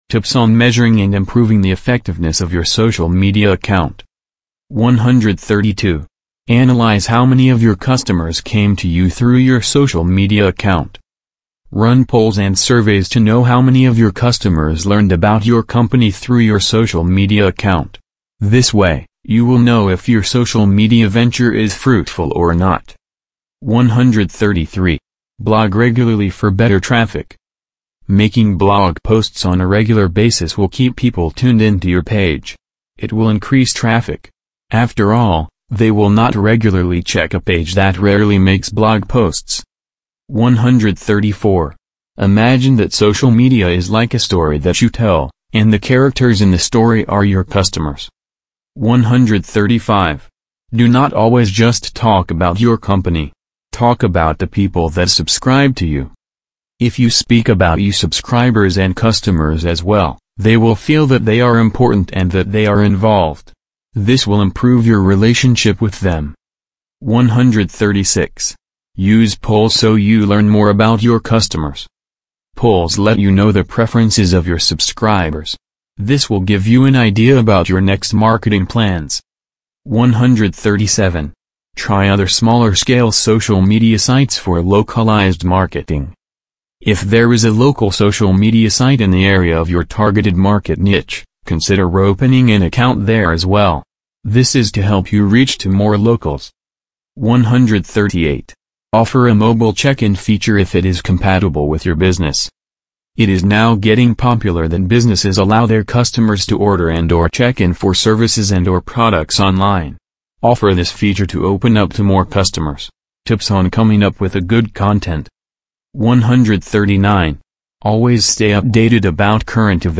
Featuring conversations with marketing leaders, CX experts, and entrepreneurs, the show uncovers what truly drives customer satisfaction and long-term relationships.